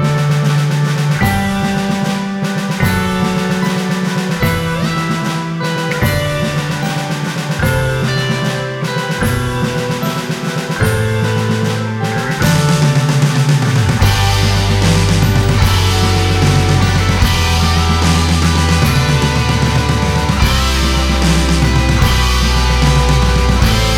no Backing Vocals Indie / Alternative 5:10 Buy £1.50